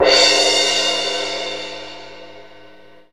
Index of /90_sSampleCDs/Roland L-CDX-01/CYM_FX Cymbals 1/CYM_Cymbal FX
CYM CRSHMA0C.wav